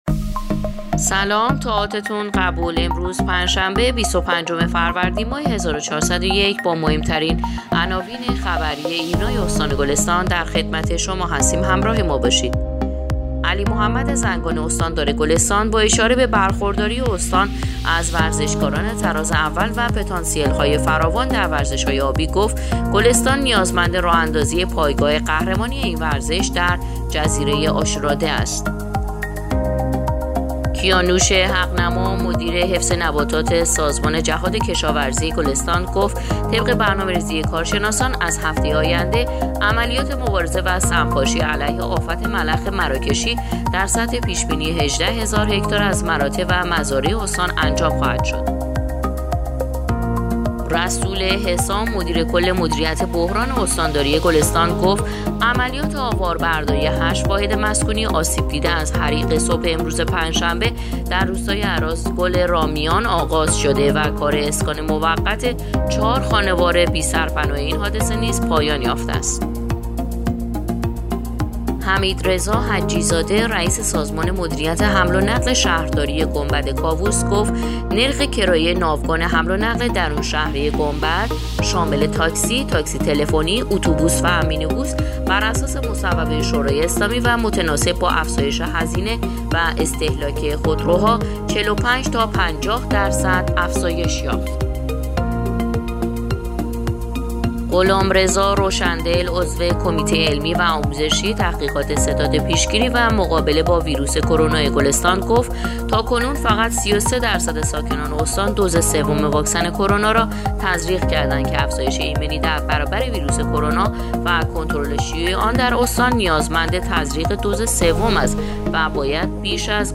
پادکست/ اخبار شبانگاهی بیست و پنجم فروردین ماه ایرنا گلستان